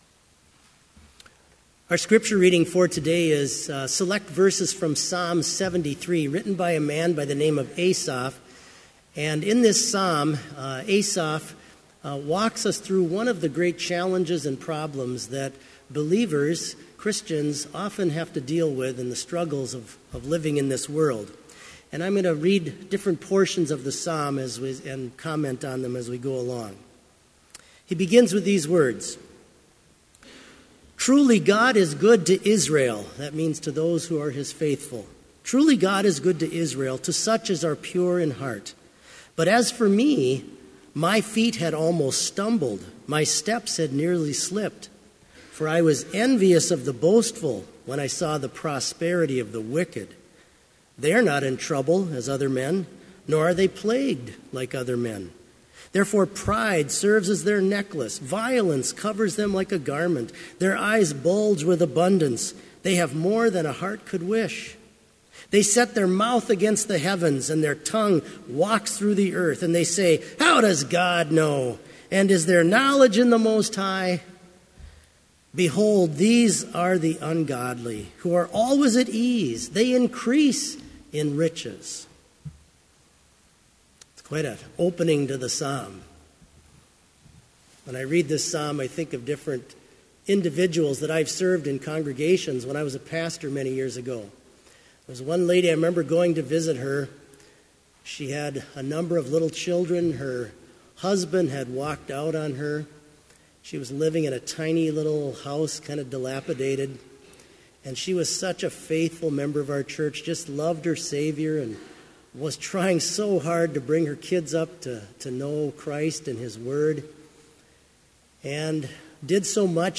Complete Service
Soloist: Give thanks… / Congregation: Give thanks to the Lord…
• Hymn 544, vv. 1 & 3, Wake, Awake for Night Is Flying
This Chapel Service was held in Trinity Chapel at Bethany Lutheran College on Friday, April 27, 2018, at 10 a.m. Page and hymn numbers are from the Evangelical Lutheran Hymnary.